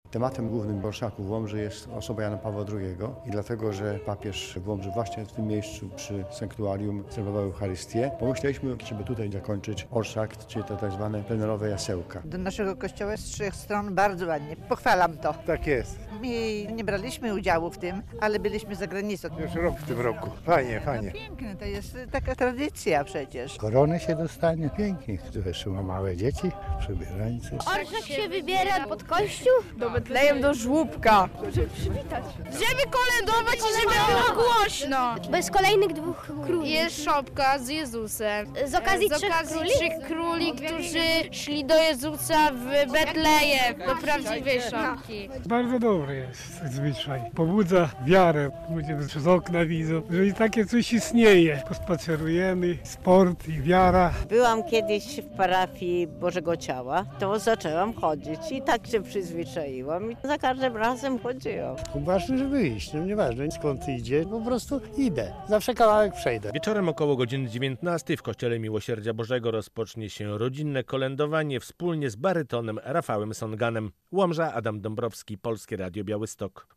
Orszak Trzech Króli w Łomży - relacja